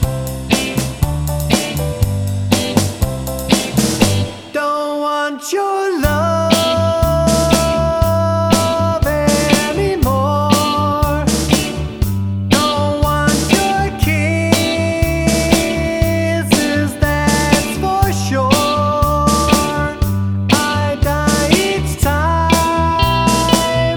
No Harmony Pop (1950s) 2:23 Buy £1.50